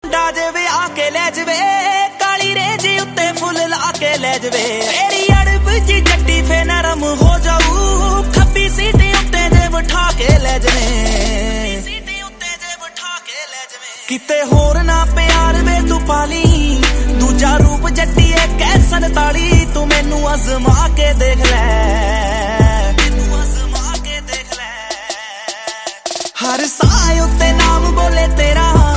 Category: Punjabi Ringtones